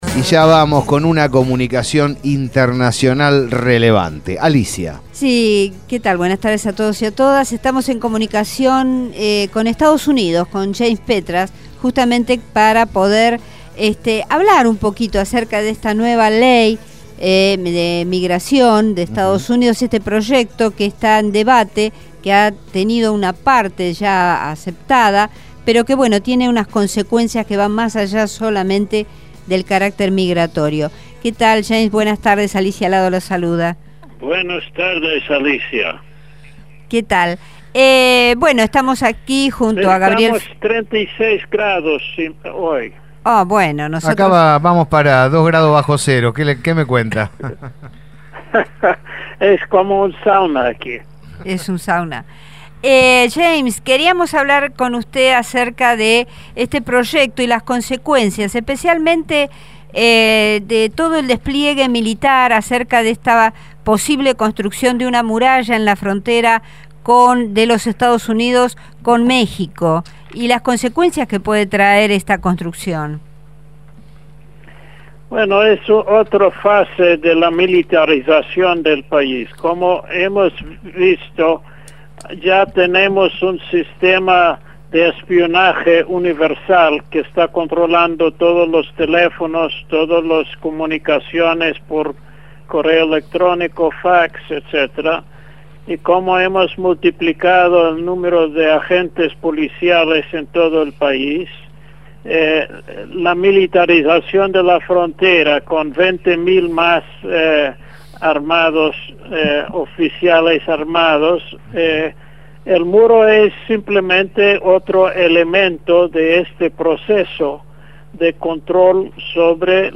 James Petras, sociólogo estadounidense, habló con los Especiales de la Gráfica.